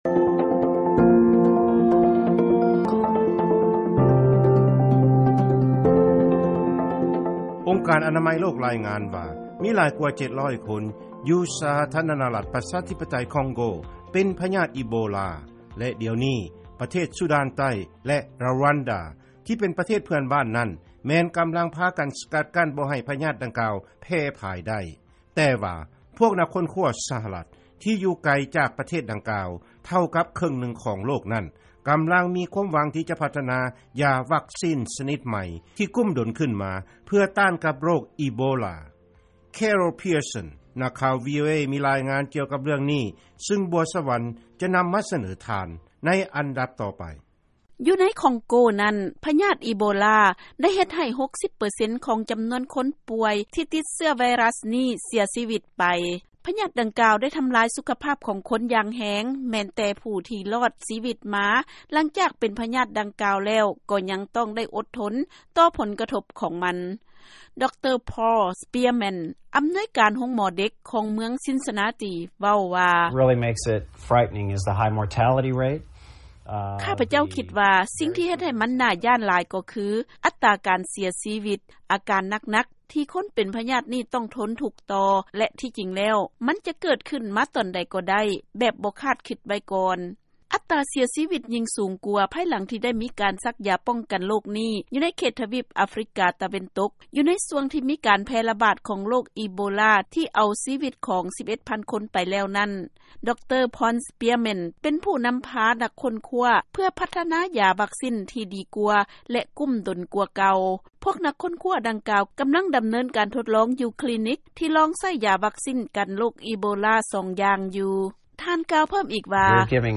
ເຊີນຟັງລາຍງານກ່ຽວກັບການພັດທະນາຢາວັກຊິນຕ້ານພະຍາດອີໂບລາ